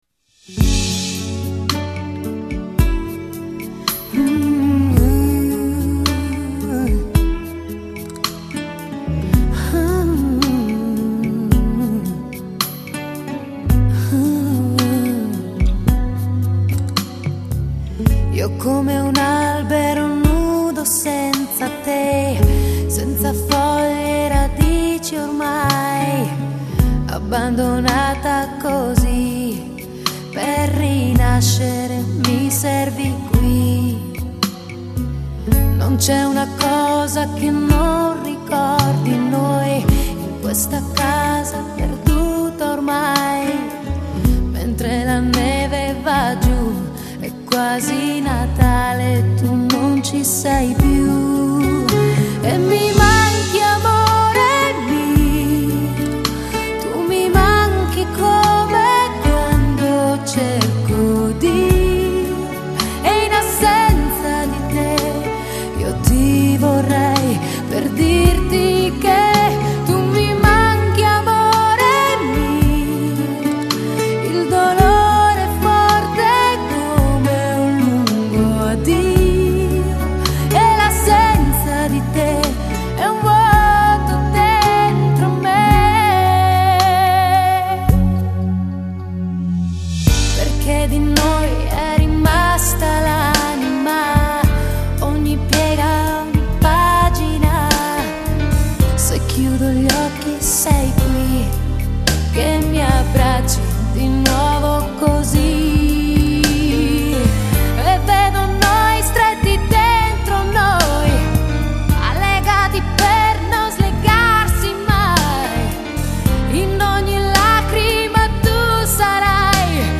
Файл с диска. Качество отличное.